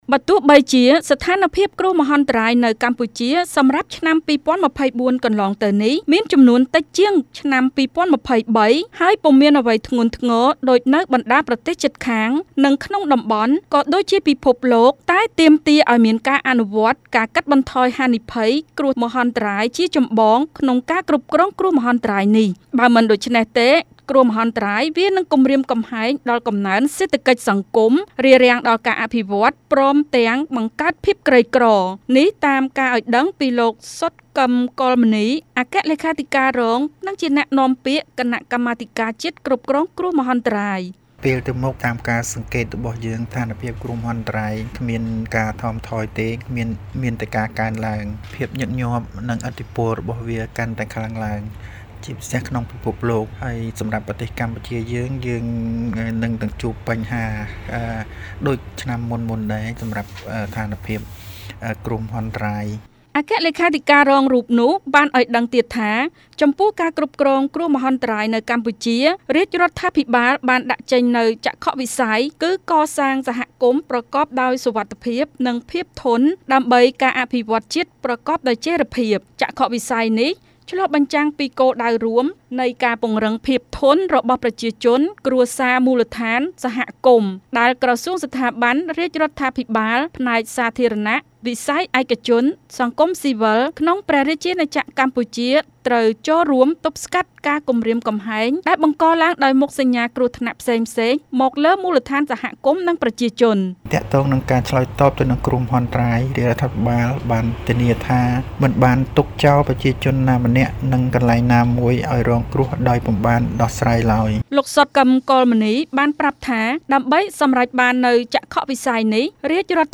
ជូនសេចក្តីរាយការណ៍